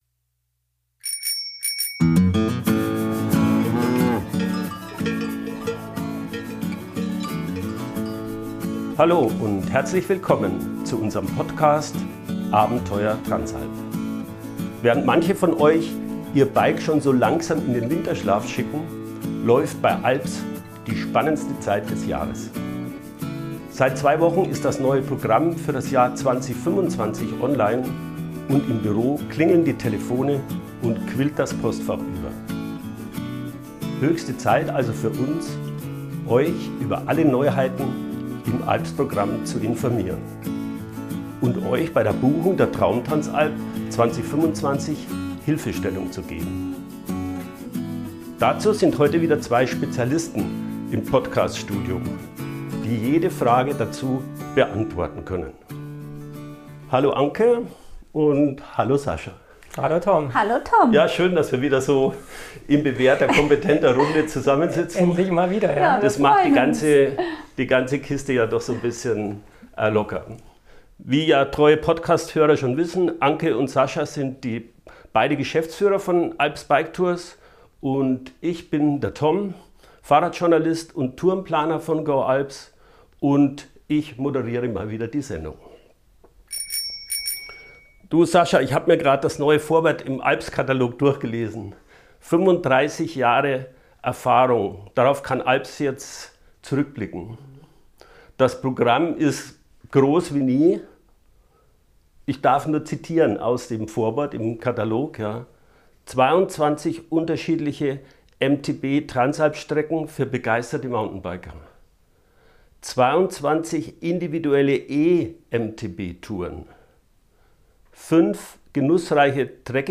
Höchste Zeit also für uns, euch über alle Neuheiten im ALPS-Programm zu informieren, und euch bei der Buchung der Traum-Transalp 2025 Hilfestellung zu geben. Dazu sind heute wieder zwei Spezialisten im Podcast Studio, die jede Frage dazu beantworten können.